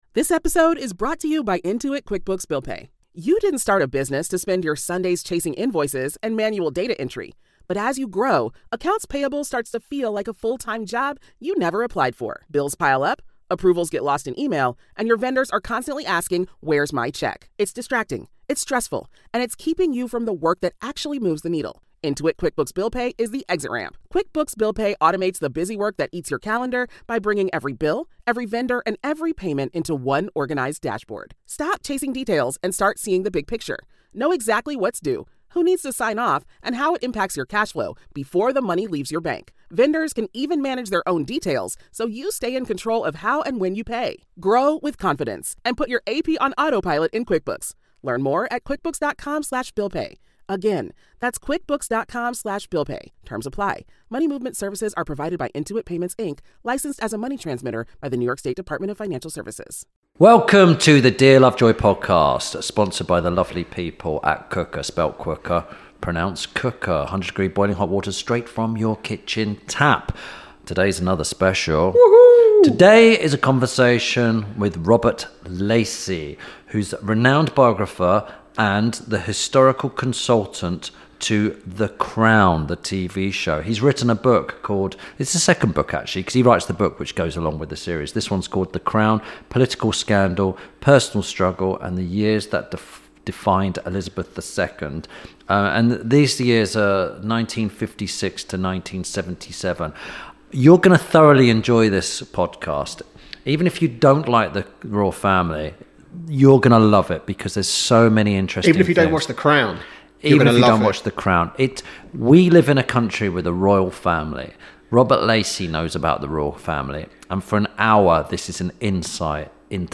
– INTERVIEW SPECIAL
This week Tim Lovejoy talks to royal historian and the historical consultant to The Crown, Robert Lacey. Tim and Robert discuss making The Crown, working with creator Peter Morgan and the future of The Crown, in both senses.